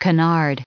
added pronounciation and merriam webster audio
943_canard.ogg